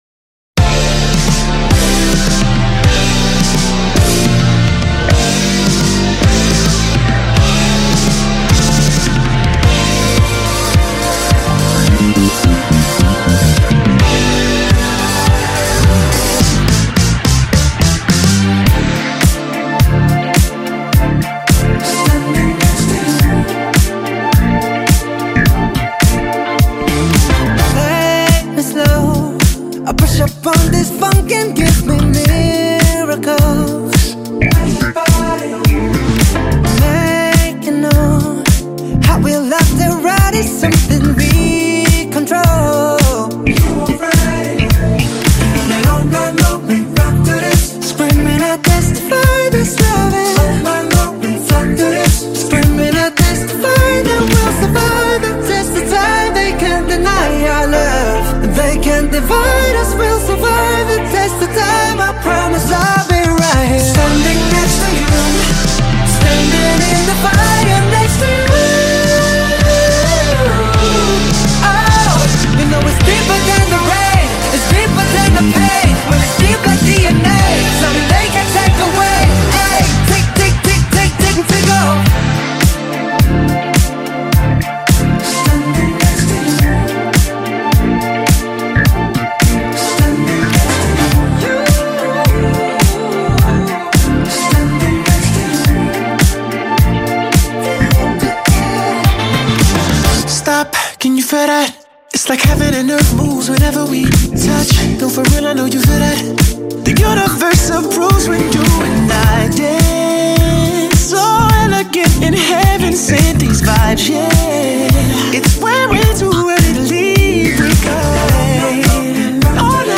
This is a remix